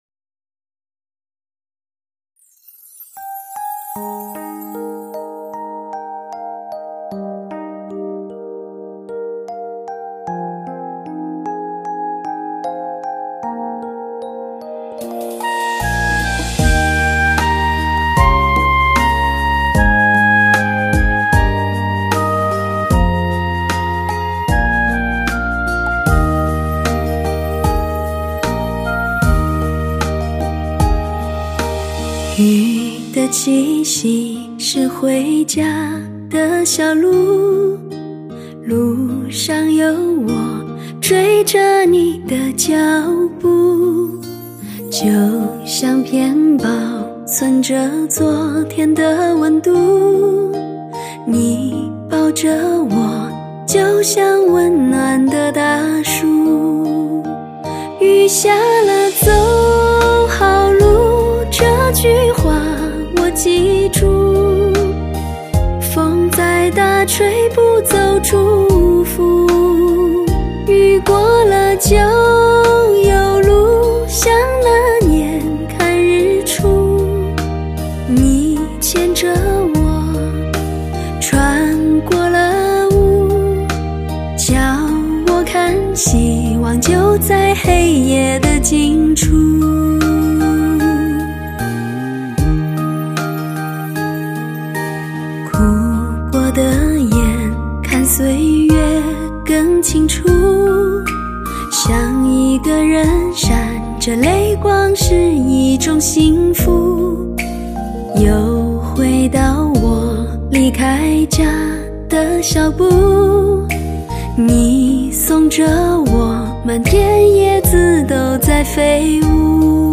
类型: 天籁人声
一个纯净的一尘不染的清澈嗓音和极致清新的风格，在隔岸的年华里低吟浅唱……带你远离都市的喧嚣